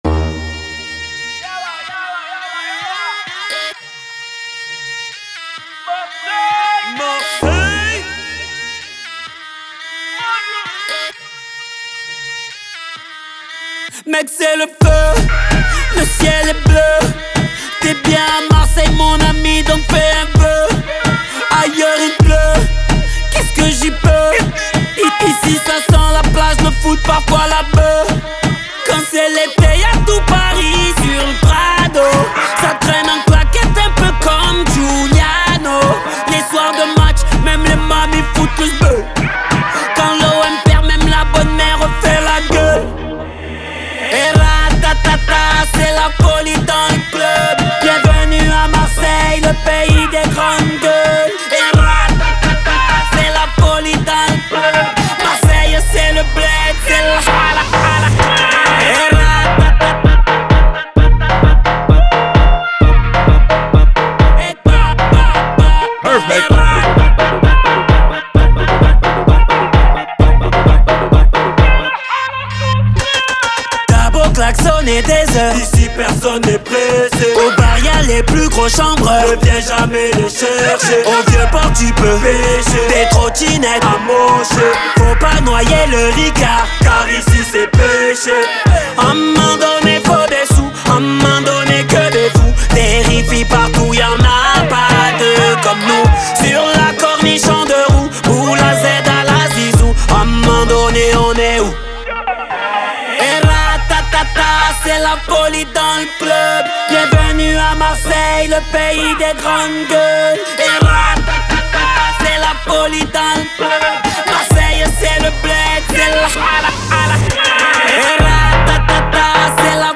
38/100 Genres : french rap Télécharger